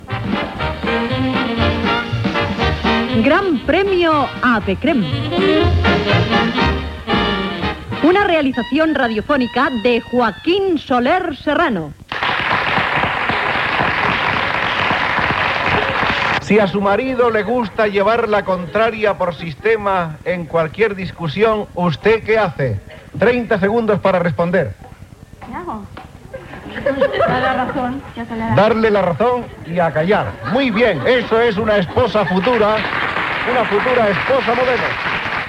Identificació del programa i dues preguntes al participant del concurs.
Soler Serrano, Joaquín